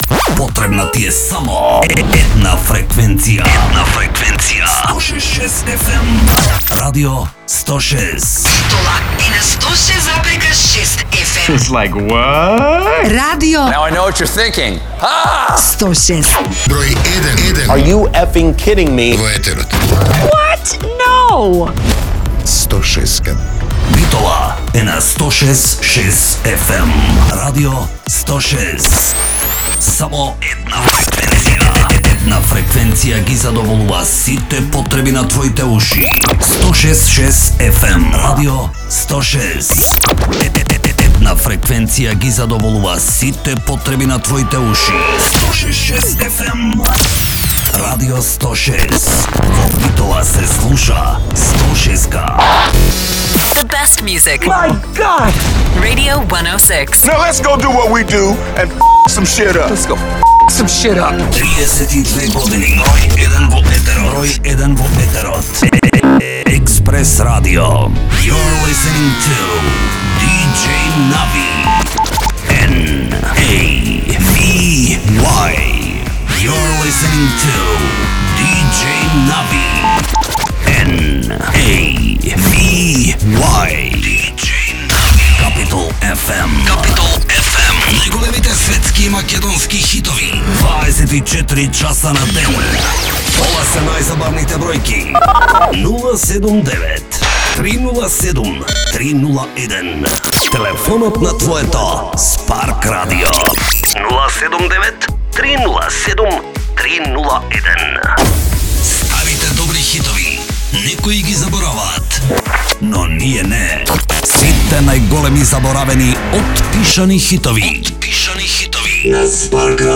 Џингл пакети за радио станици (свипери, џинглови за идентификација на радија, почетни џинглови за емисии, радио најави, џинглови за спецификација на програми и слично).
Демо матерјалите се со голема mp3 компресија, а оригиналната продукција е со многу повисок квалитет!
Демо 8 (Radio Jingles and Sweepers 2025)
Demo 8 (Radio Jingles and Sweepers 2025).mp3